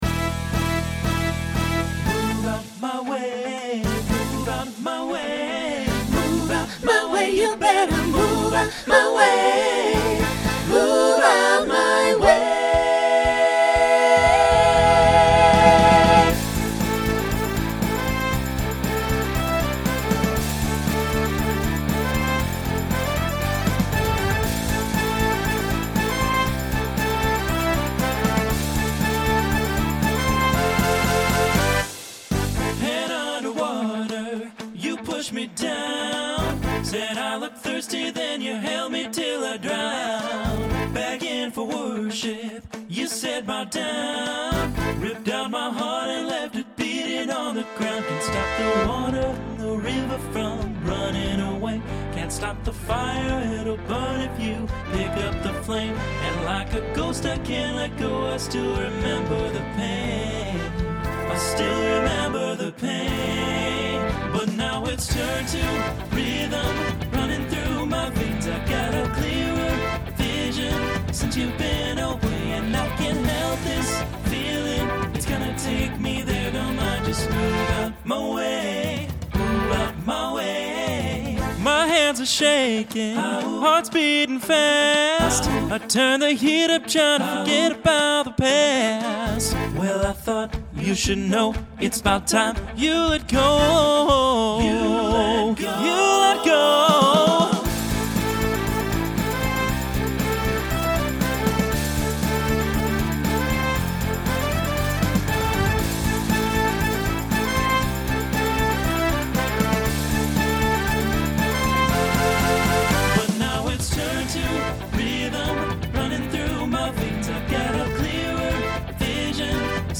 Pop/Dance , Rock
Transition Voicing Mixed